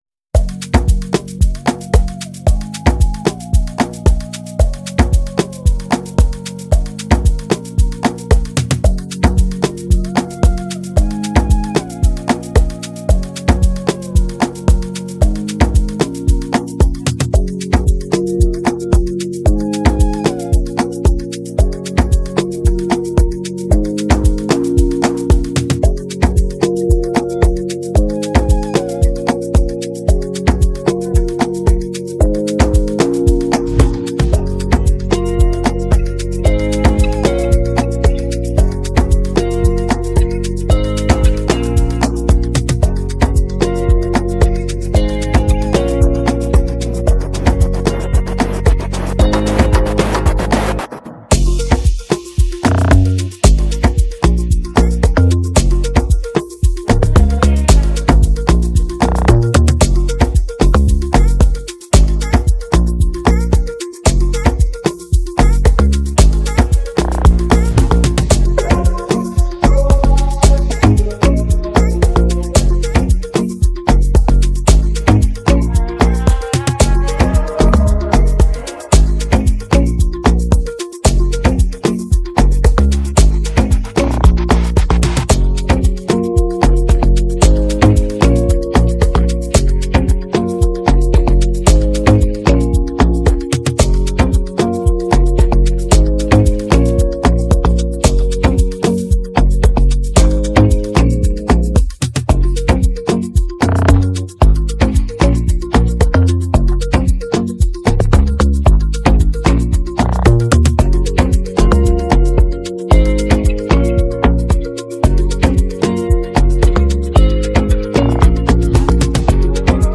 Afrobeats
Tagged amapiano